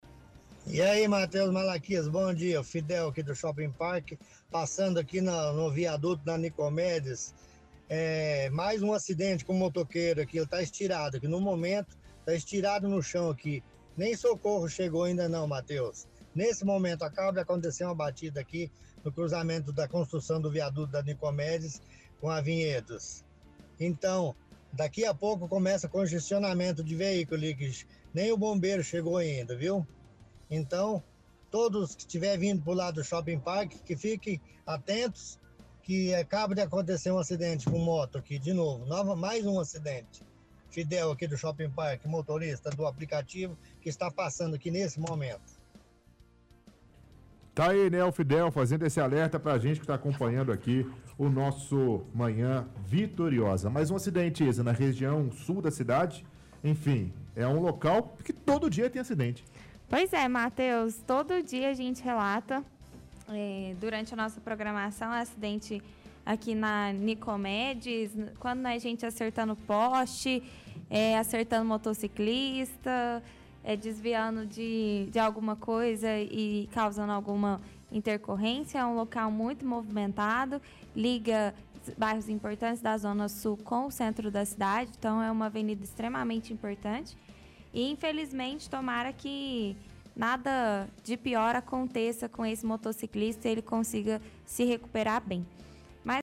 – Ouvinte relata acidente próximo a obra do viaduto da av. Vinhedos envolvendo motoqueiro.